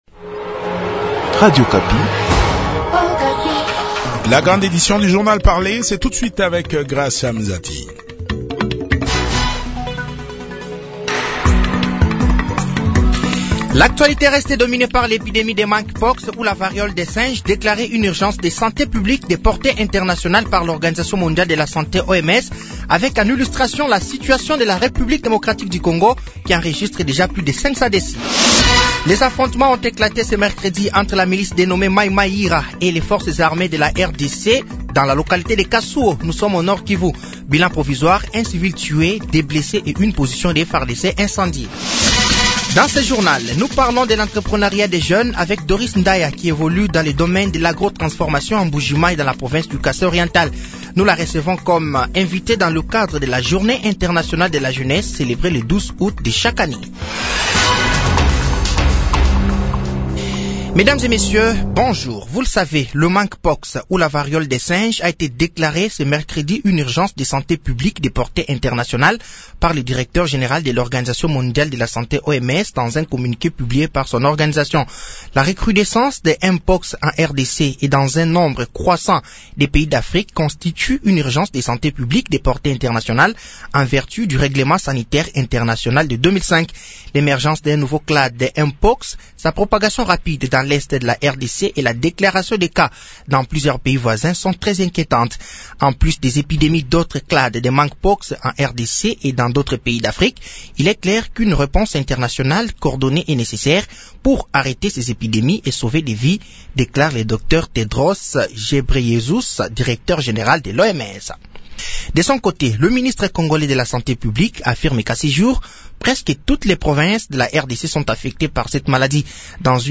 Journal français de 12h de ce jeudi 15 août 2024